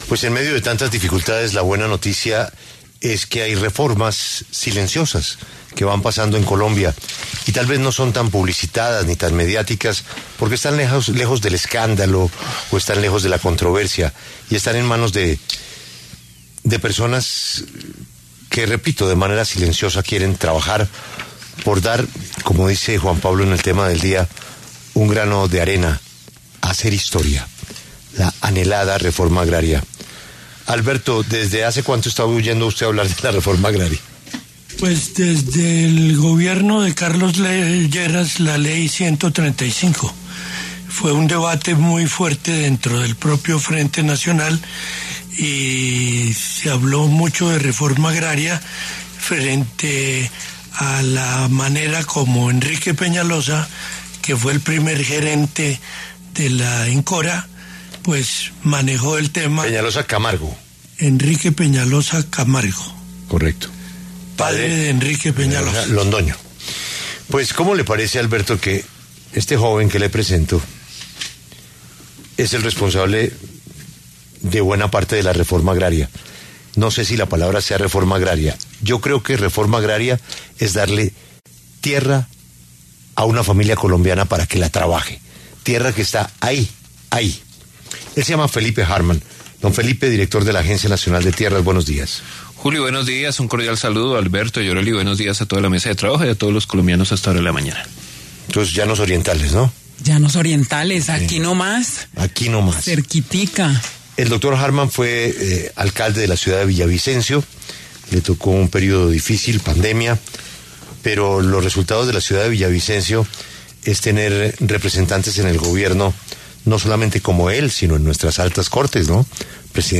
Felipe Harman, director de la Agencia Nacional de Tierras (ANT), habló en La W acerca de las cifras de restitución de tierras que se han logrado en su administración.